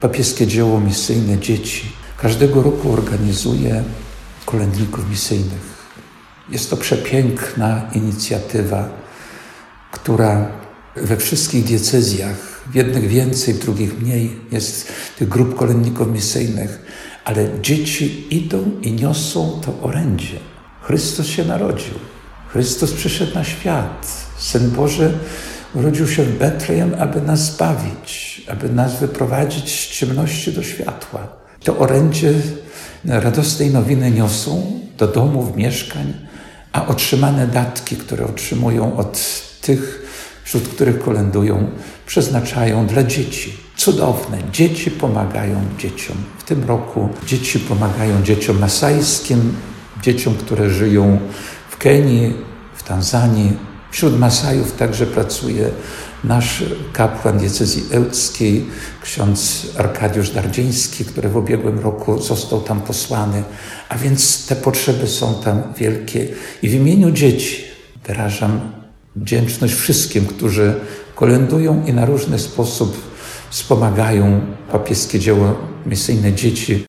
Biskup diecezji ełckiej ksiądz Jerzy Mazur podkreśla, że mimo wymuszonych zmian idea uroczystości Objawienia Pańskiego pozostaje niezmienna.
Ksiądz biskup Jerzy Mazur zwraca także uwagę na inny aspekt tego święta.